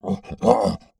MONSTER_Effort_04_mono.wav